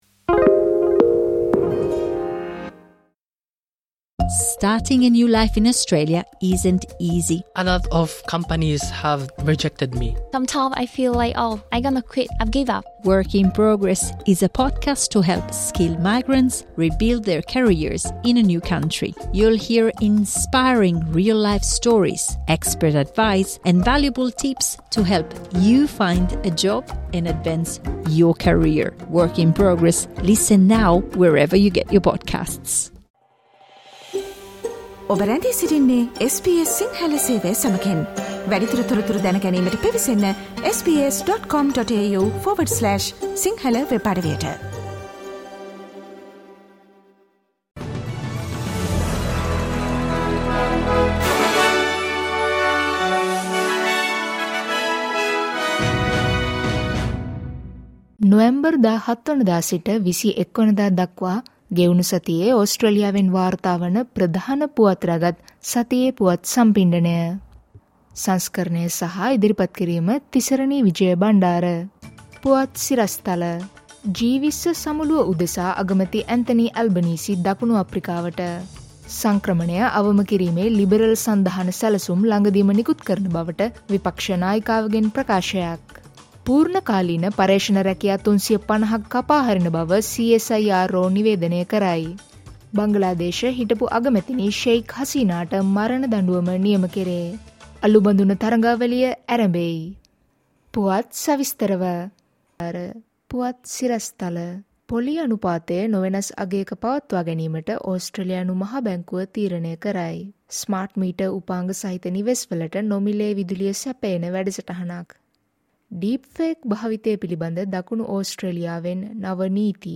නොවැම්බර් 17වන දා සිට නොවැම්බර් 21වන දා දක්වා සතියේ ඕස්ට්‍රේලියාවෙන් වාර්තා වන පුවත් ඇතුළත් සතියේ පුවත් ප්‍රකාශයට සවන් දෙන්න.